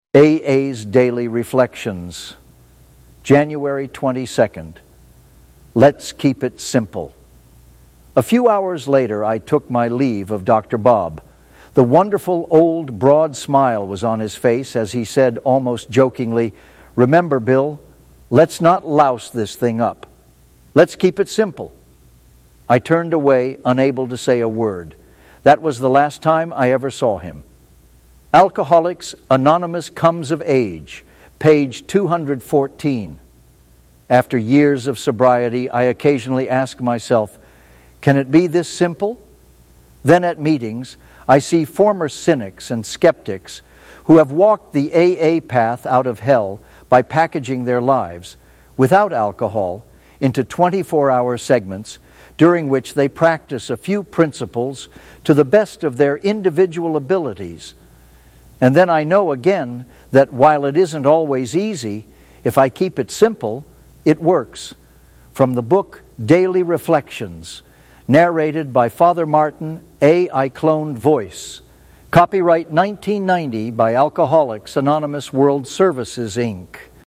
A.I. Cloned Voice